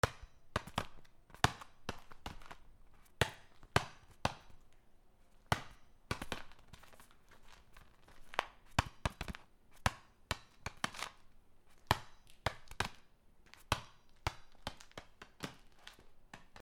/ G｜音を出すもの / Ｇ-15 おもちゃ
『トントン』